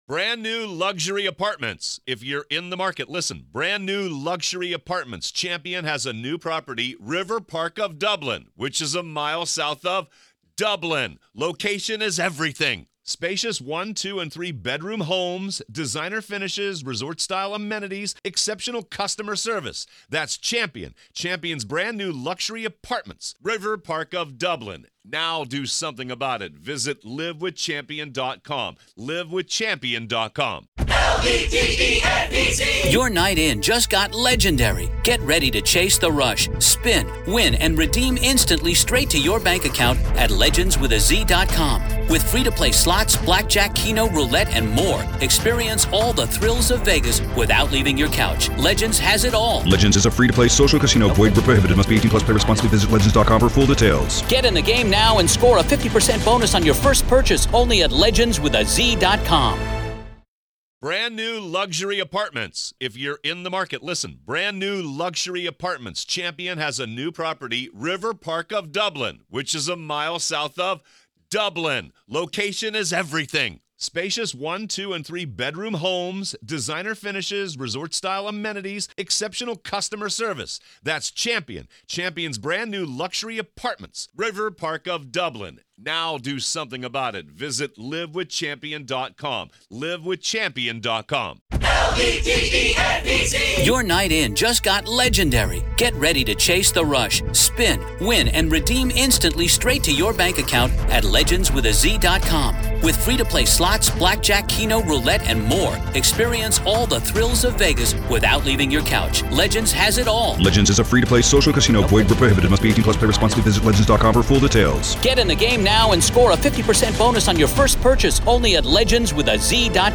This is audio from the courtroom in the high-profile murder retrial